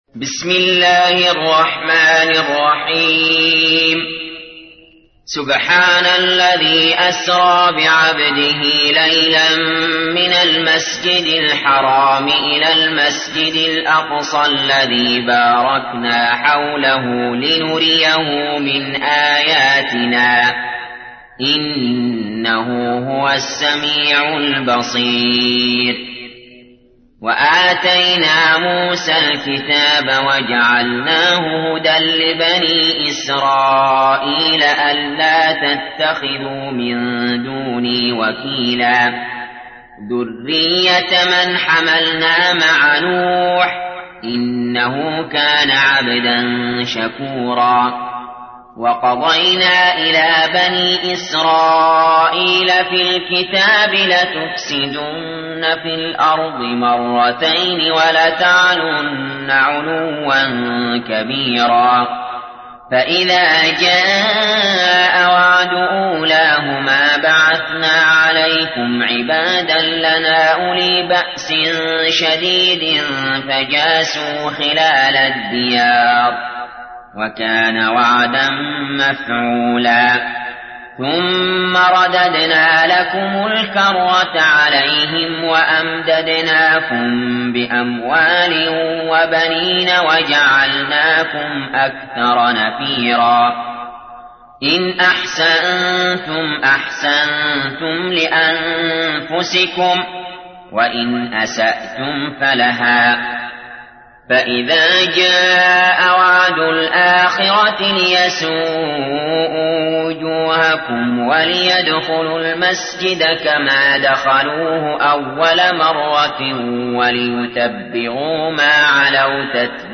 تحميل : 17. سورة الإسراء / القارئ علي جابر / القرآن الكريم / موقع يا حسين